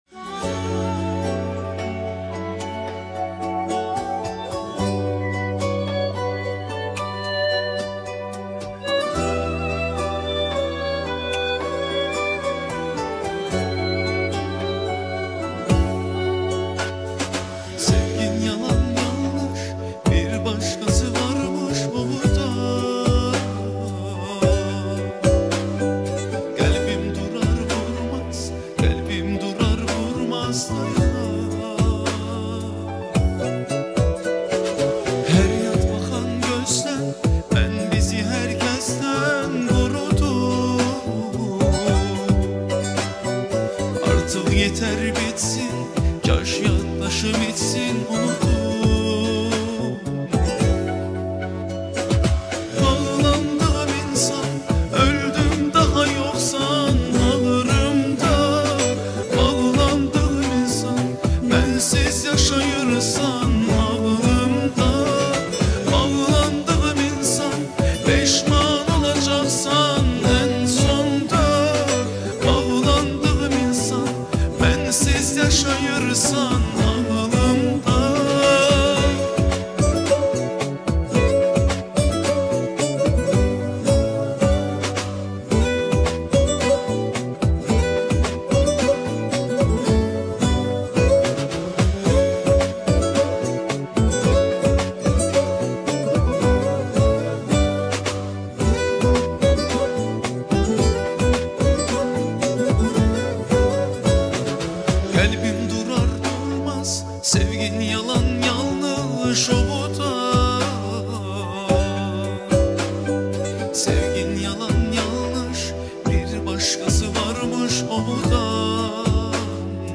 MÖHTƏŞƏM CANLI İFA